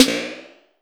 Index of /90_sSampleCDs/USB Soundscan vol.20 - Fresh Disco House I [AKAI] 1CD/Partition C/09-SNARES